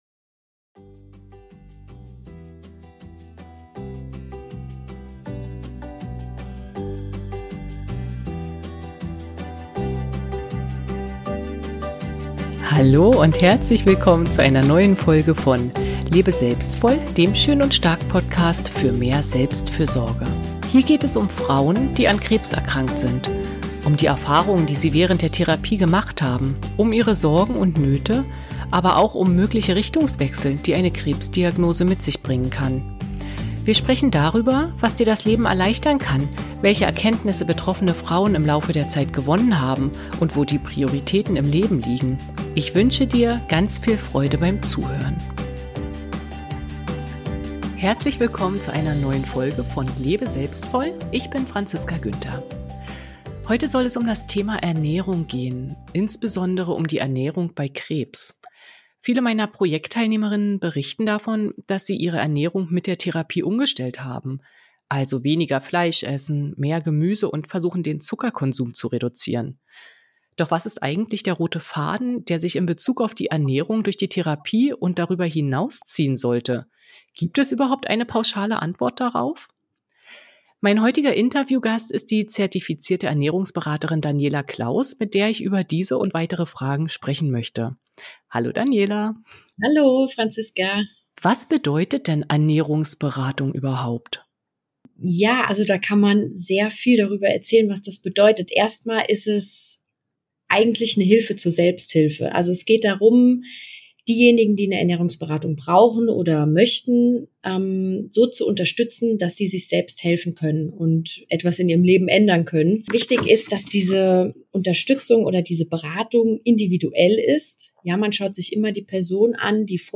im Gespräch mit Ernährungsberaterin